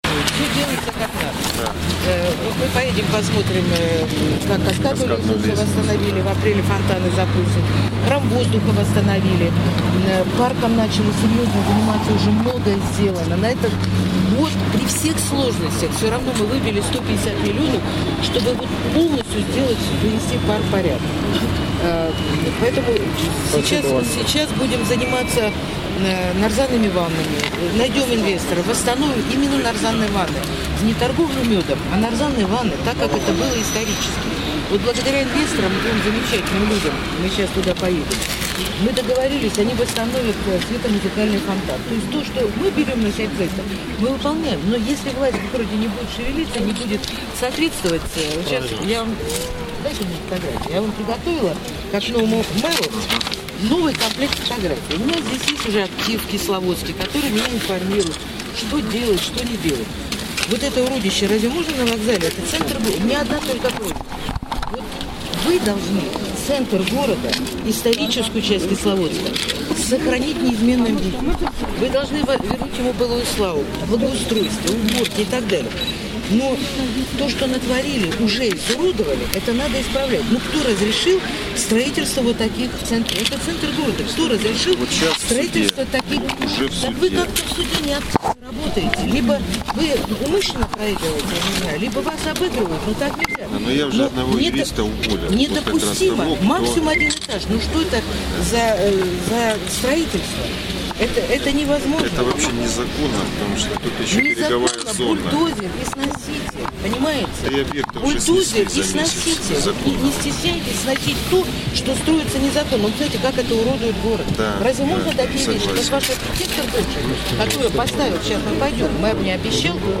Для тех читателей, кого интересуют его детали, мы размещаем полную аудиозапись, сделанную в самом начале встречи.